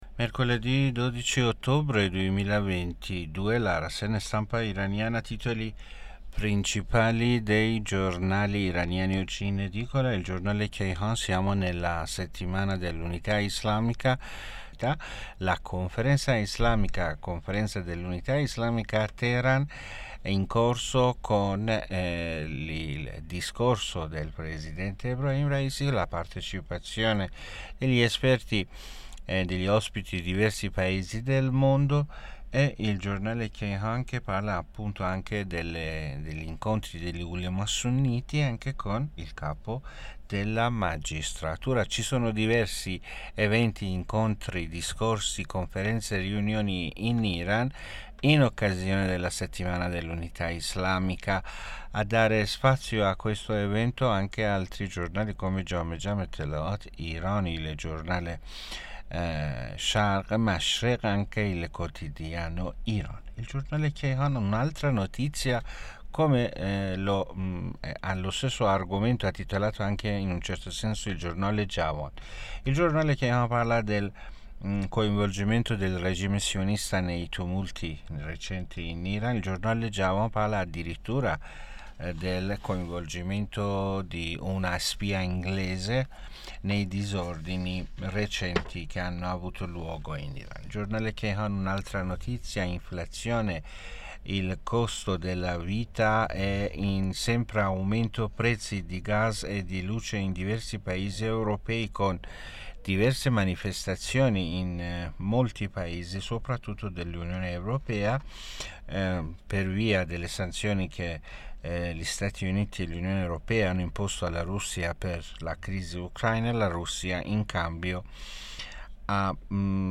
Rassegna Stampa Iran Mercoledi' 12 Ottobre 2022 (AUDIO)